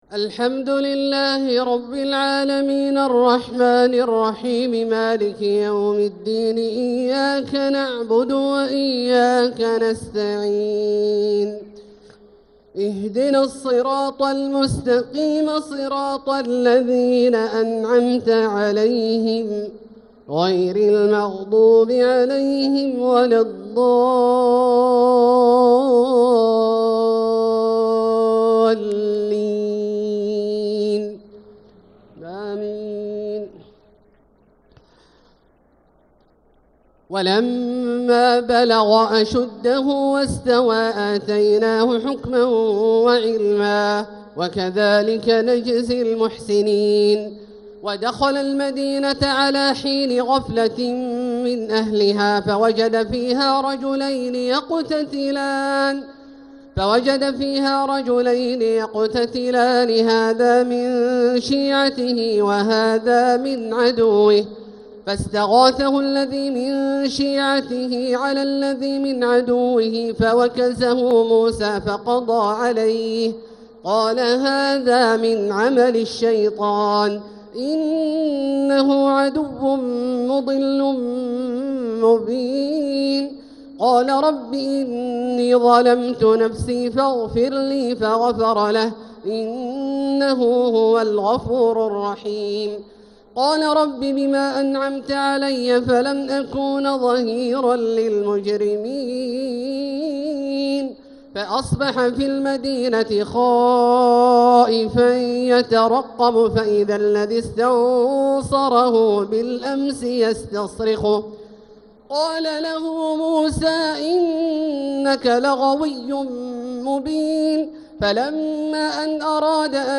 تهجد ليلة 23 رمضان 1446هـ من سورتي القصص (14-88) و العنكبوت (1-27) | Tahajjud 23rd night Ramadan 1446H Surah Al-Qasas and Al-Ankaboot > تراويح الحرم المكي عام 1446 🕋 > التراويح - تلاوات الحرمين